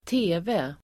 Ladda ner uttalet
TV Uttal: [²t'e:ve:]